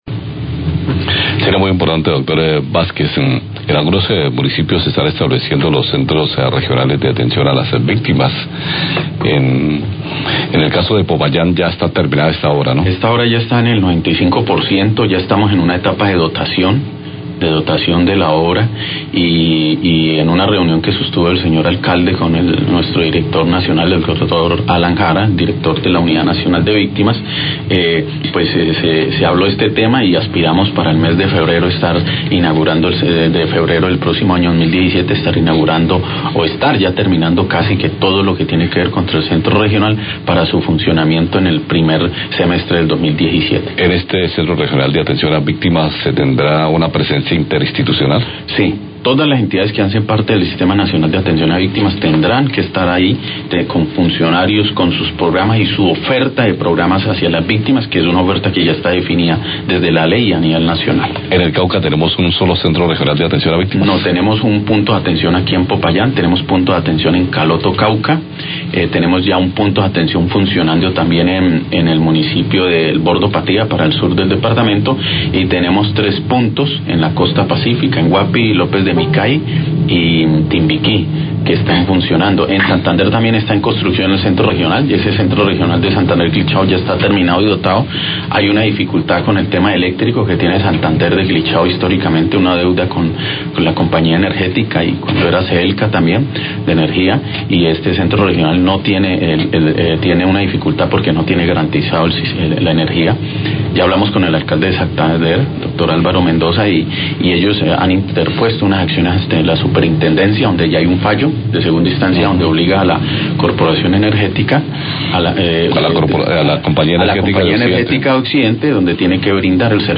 Radio
Agrega que ya hay un fallo a favor de restablecer el servicio a este centro.